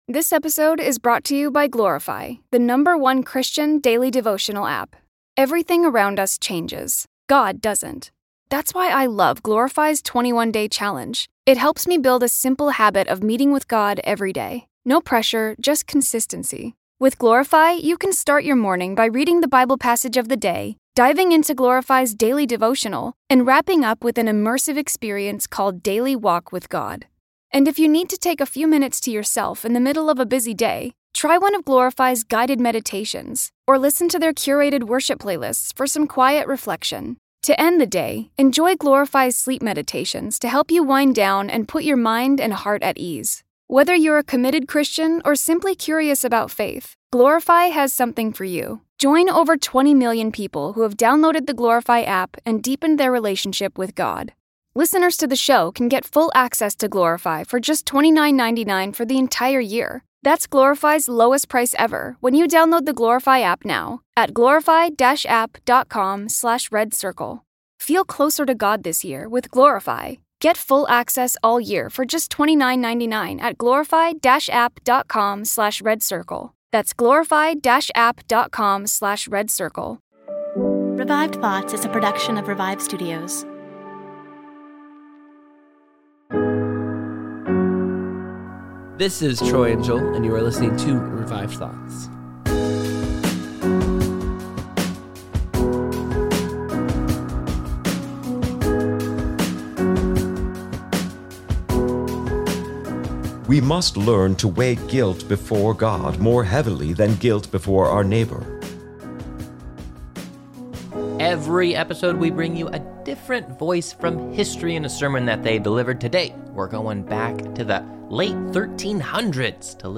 Revived Thoughts EXCLUSIVE - Jan Hus: Forgiveness Play episode February 5 1h 2m Bookmarks Episode Description EXCLUSIVE: For the first time in 611 years, the sermons of Jan Hus are in English.